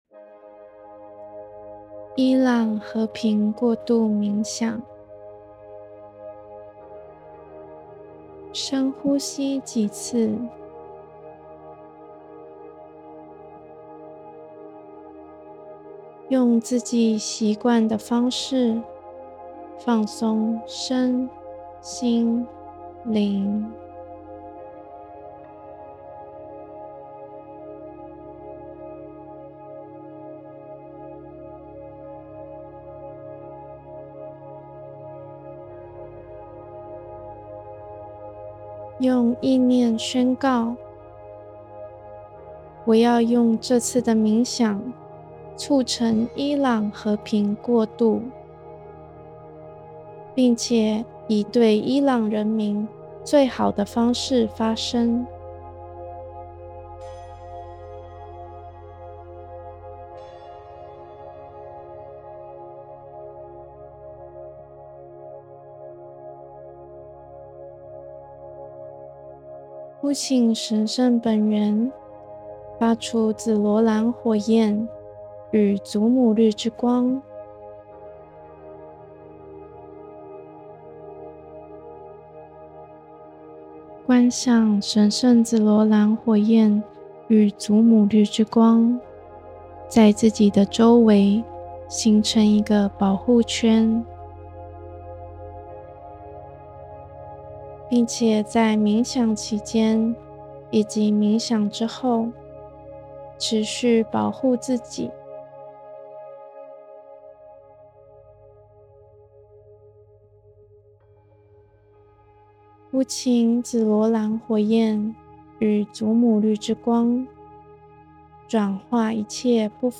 下載中文冥想音頻